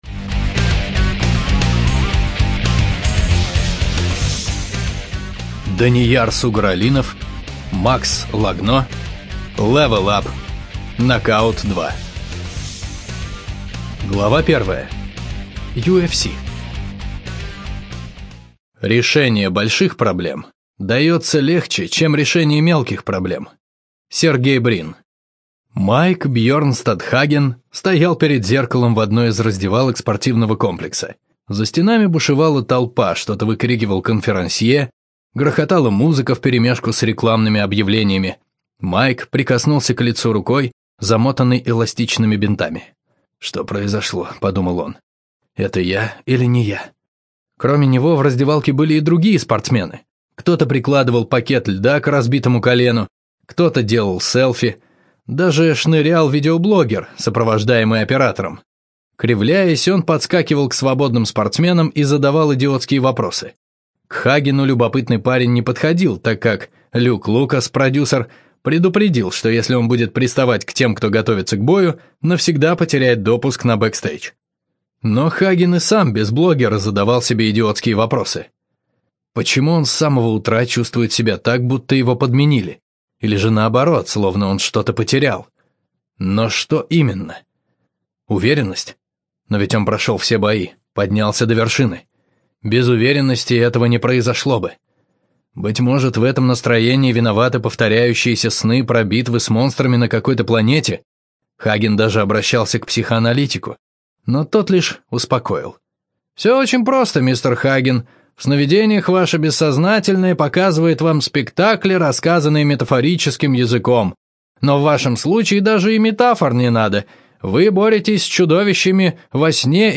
Аудиокнига Level Up. Хаген. Нокаут 2 | Библиотека аудиокниг